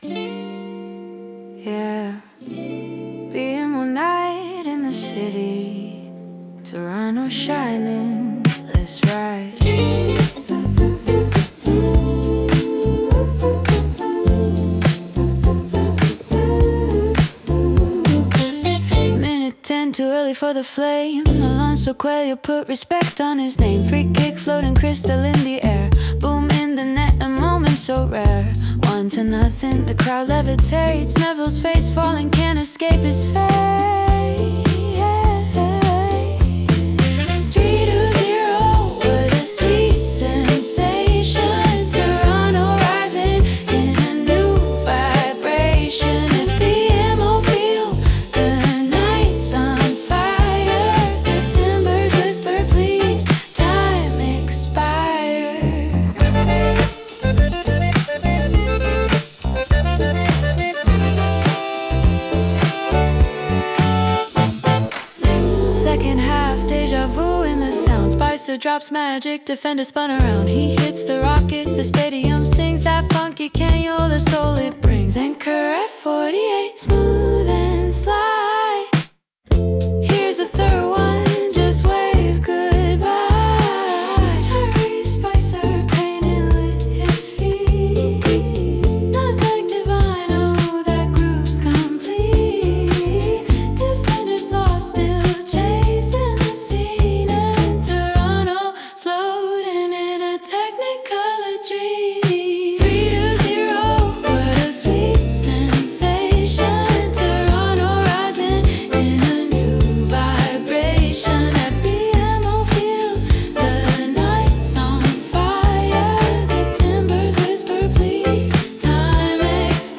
Estilo: Neo-soul / psychedelic pop, bajo funky, flauta etérea, coros pegajosos
La celebración sonora: una canción que narra con alegría el partido perfecto, el 3–0 que lo tenía todo —golazo de pelota parada, caños humillantes y el sello del delantero. Es el lado luminoso del proyecto: ritmo bailable, coros para que el público cante y un puente instrumental donde la flauta acaricia la victoria.